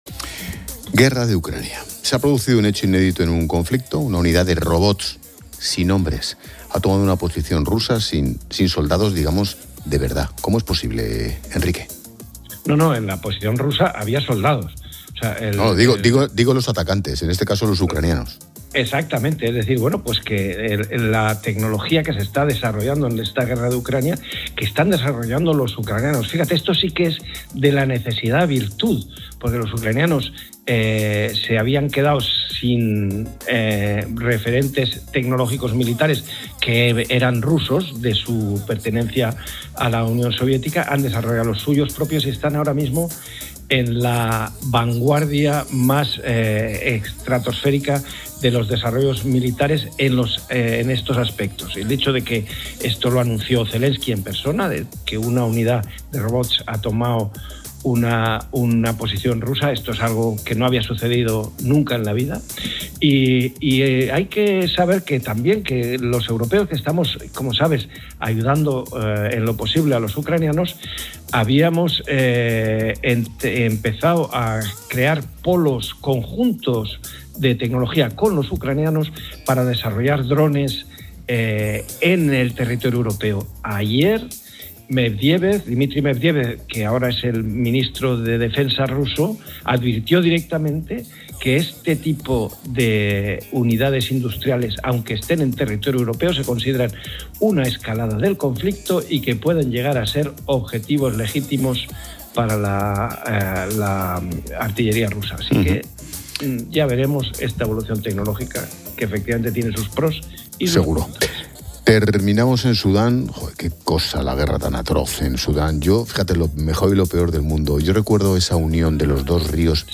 Ángel Expósito pone El Foco de La Linterna